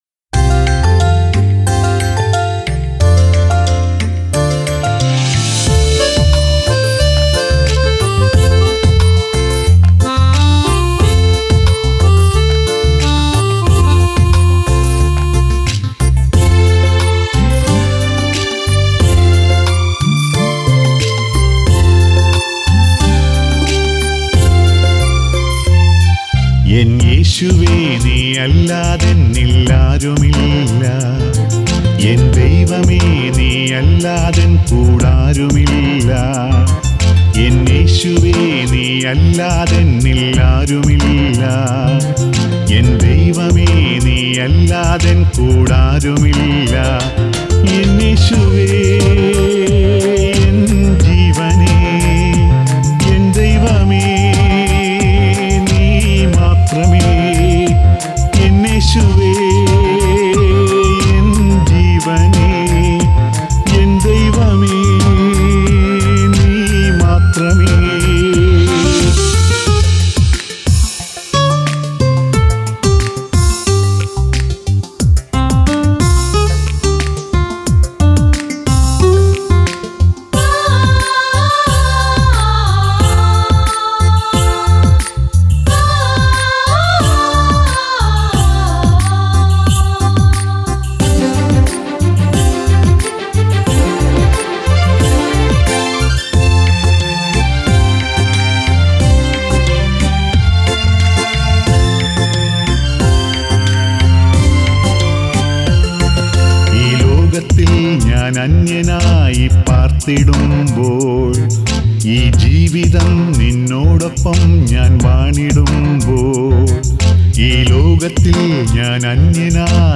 Keyboard Sequence
Rhythm Sequence
Flute
Tabala & Percussion